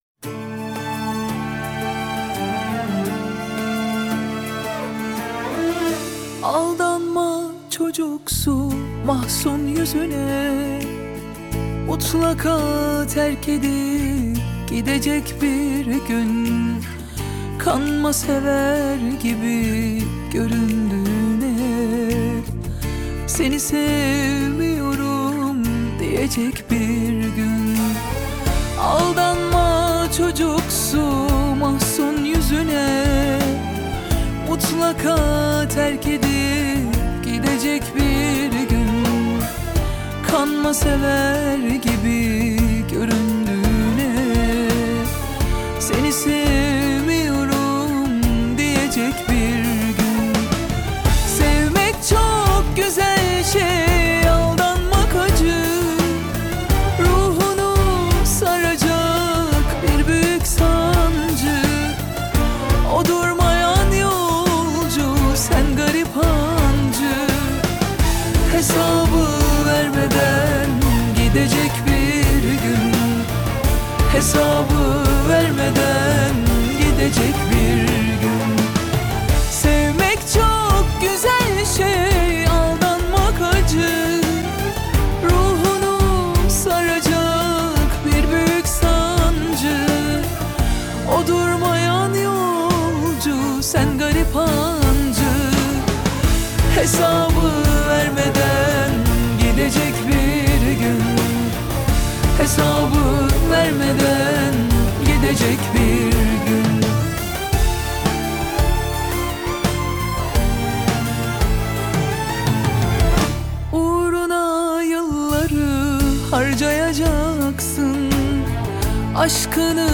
دسته بندی : آهنگ ترکی تاریخ : یکشنبه ۶ فروردین ۱۴۰۲